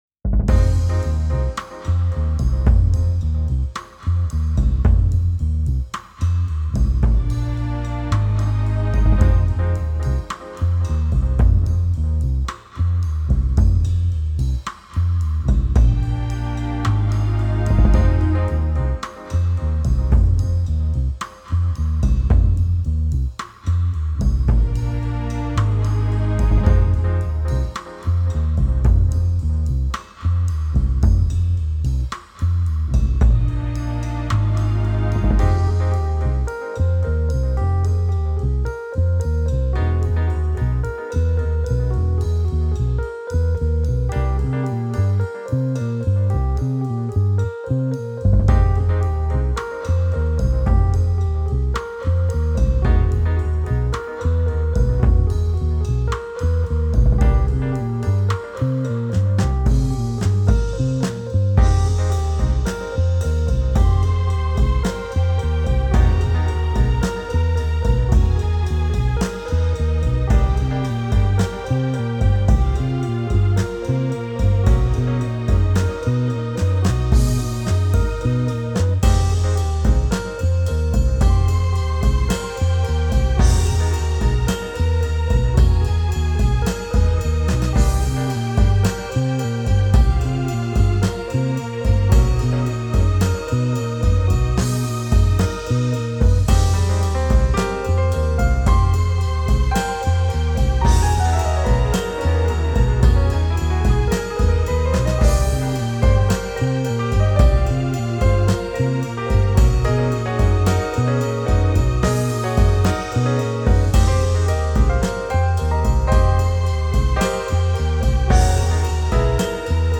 A grand hall to host evenings of mellow nostalgia